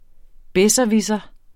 Udtale [ ˈbεsʌˌvisʌ ]